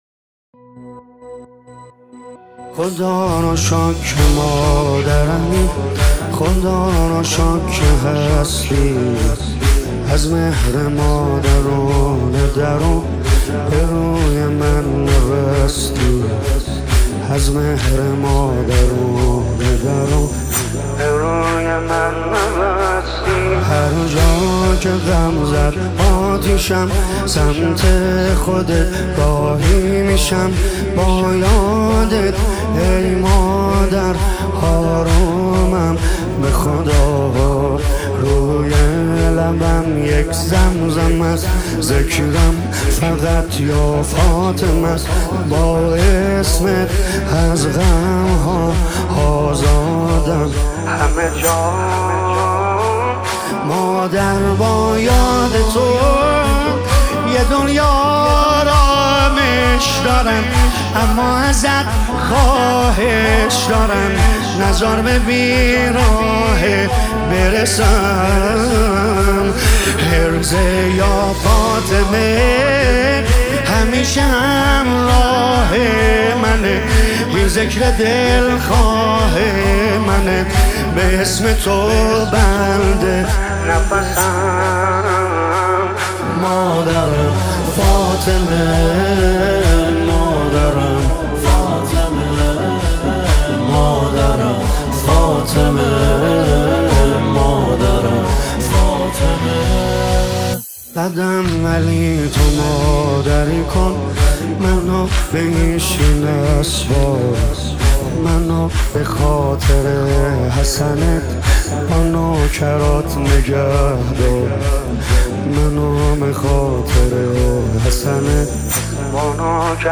نماهنگ مذهبی مداحی مذهبی